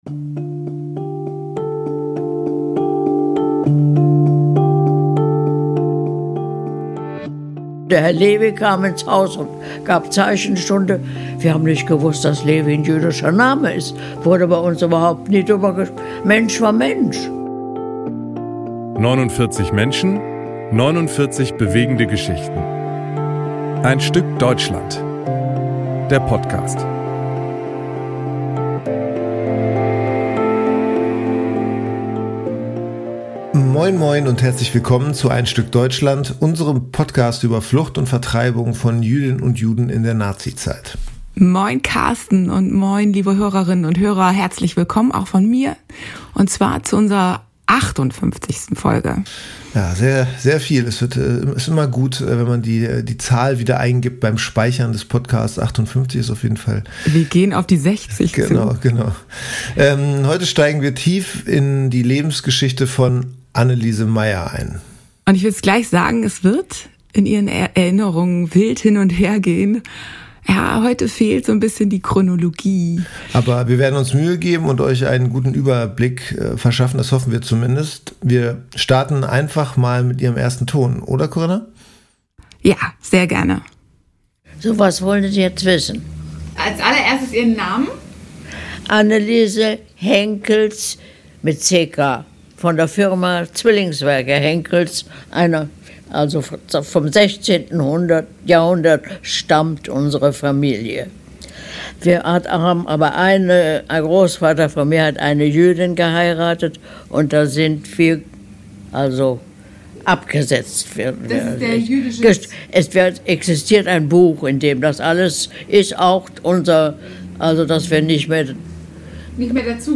Sie erzählt anekdotisch, und bis sie die Fakten herausrückt, dauert es.